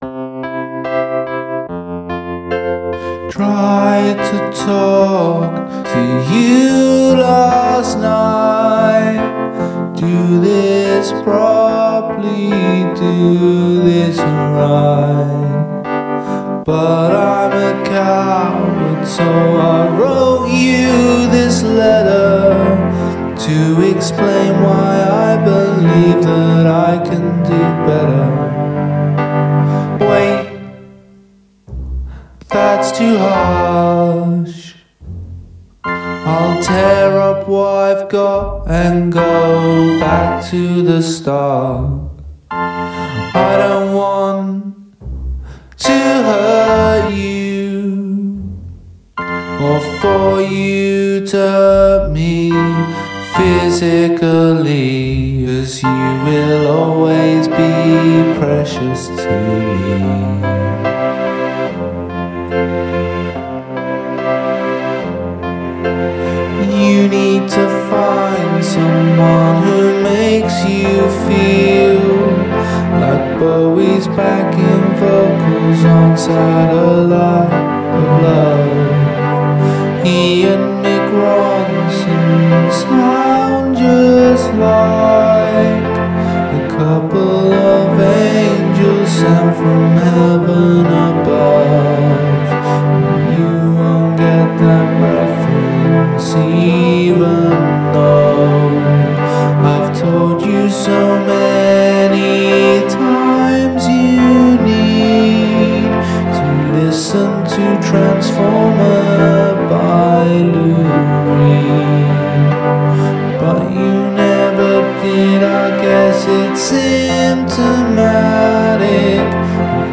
Gradual emphasis of repetitions
Your voice can't seem to find the melody.